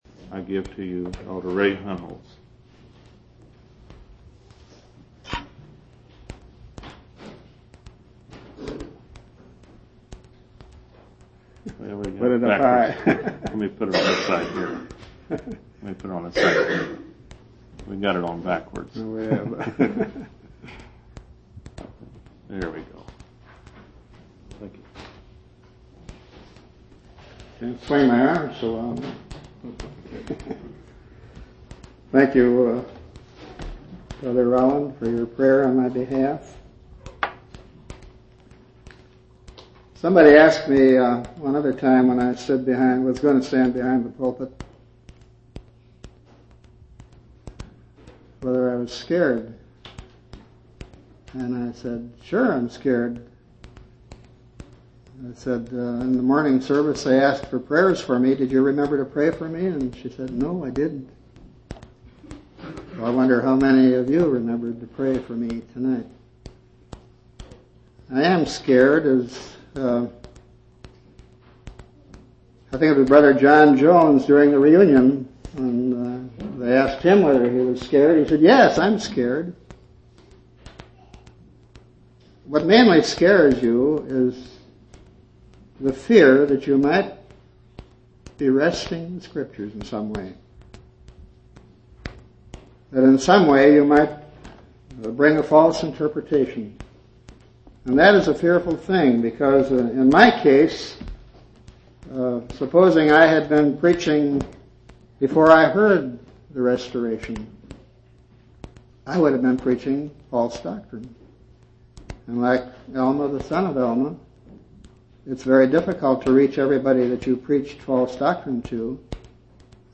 10/4/1981 Location: Temple Lot Local Event